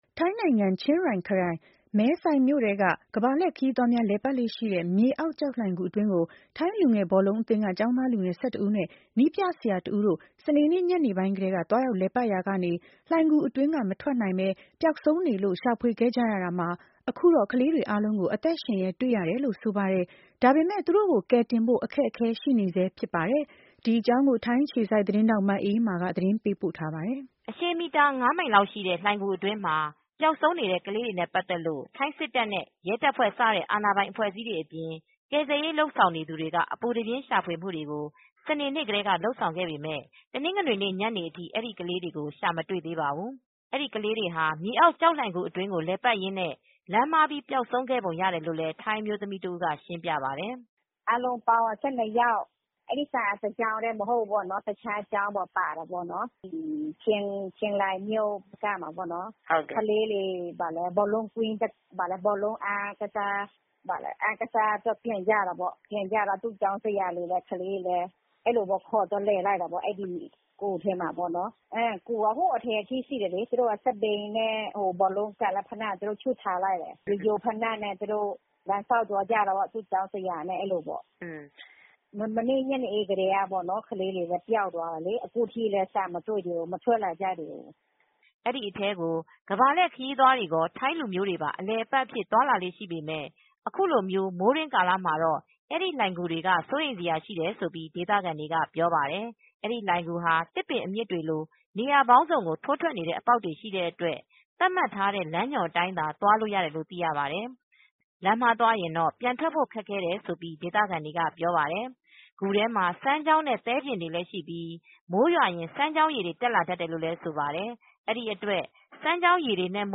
အဲဒီကလေးတွေဟာ မြေအောက်ကျောက်လှိုင်ဂူအတွင်းကို လည်ပတ်ရင်းနဲ့ လမ်းမှားပြီး ပျောက်ဆုံးခဲ့ပုံရတယ်လို့လည်း ထိုင်းအမျိုးသမီးတဦးက ရှင်းပြပါတယ်။